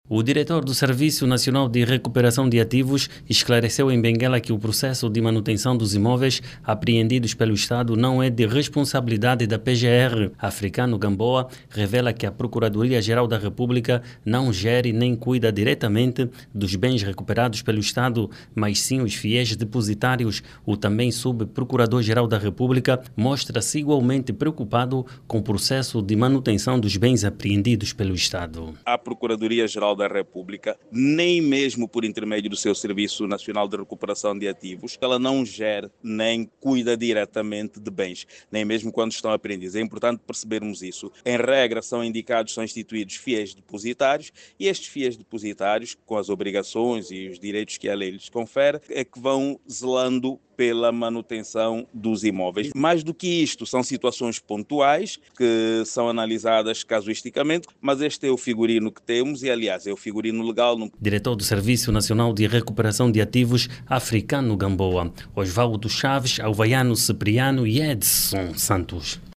O órgão fiscalizador da legalidade diz que esta função compete aos fiéis depositários que são indicados para zelar pela gestão destes imóveis. Jornalista